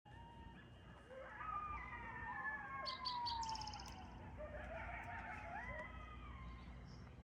Howling Coyotes Téléchargement d'Effet Sonore
Howling Coyotes Bouton sonore